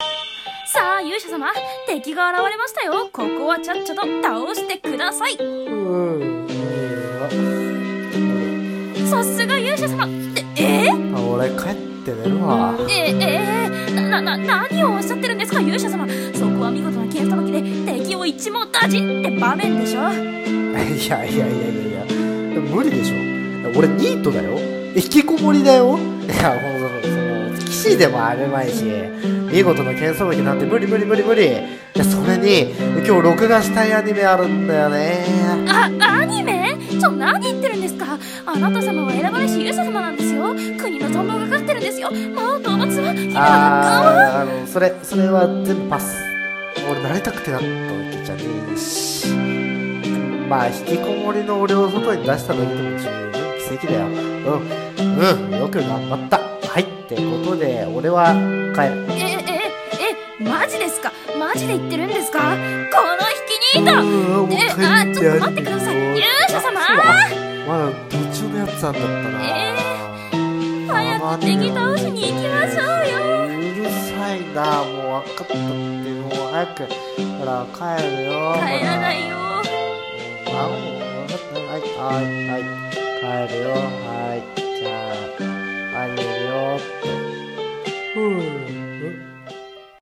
【声劇】やる気のない勇者【掛け合い】